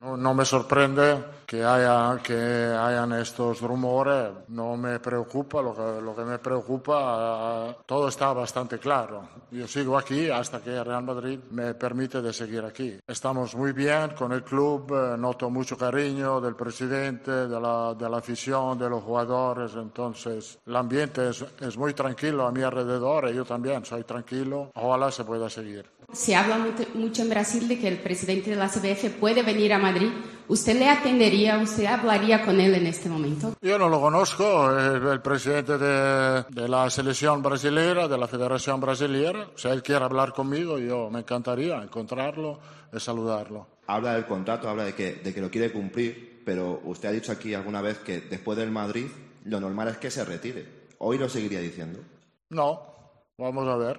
Rueda de prensa | Real Madrid